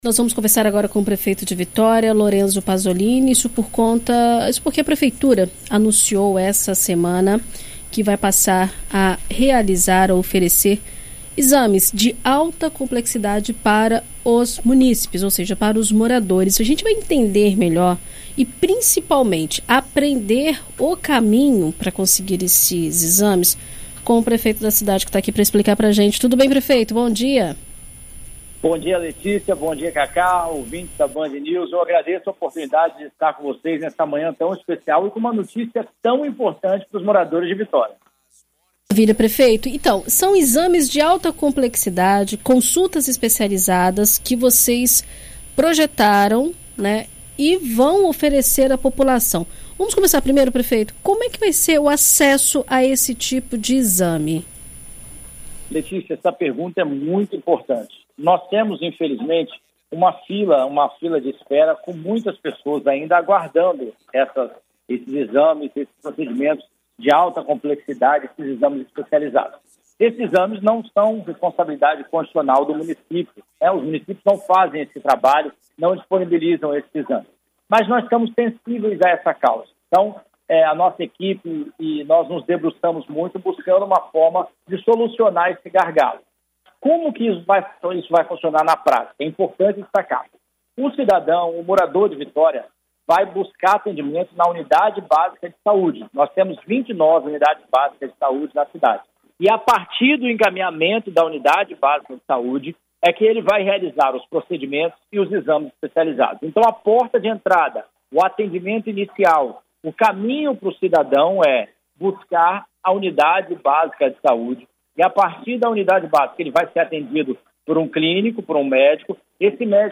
Em entrevista à BandNews FM Espírito Santo nesta terça-feira (27), o prefeito de Vitória, Lorenzo Pazolini, fala sobre os exames de alta complexibilidade que passarão a ser ofertados pelo município, entre eles: ressonância, tomografia, densitometria, cintilografia, eletroencefalograma e polissonografia.